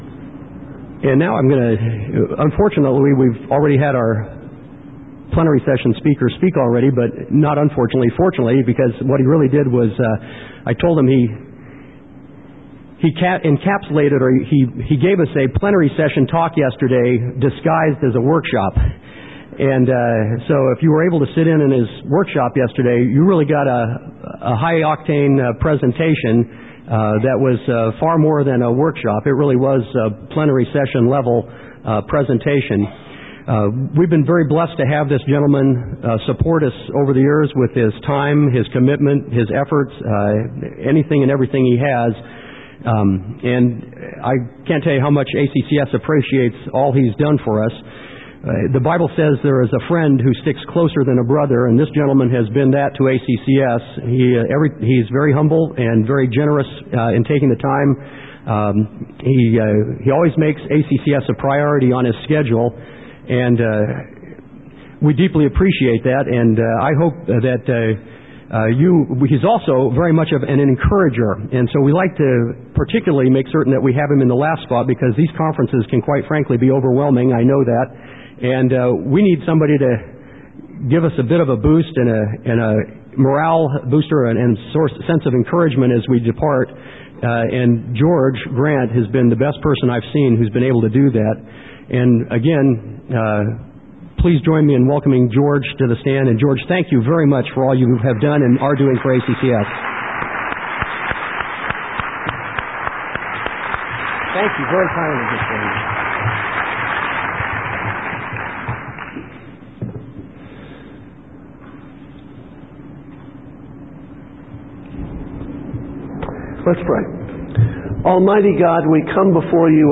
2003 Foundations Talk | 0:45:22 | All Grade Levels, Virtue, Character, Discipline
Mar 11, 2019 | All Grade Levels, Conference Talks, Foundations Talk, Library, Media_Audio, Virtue, Character, Discipline | 0 comments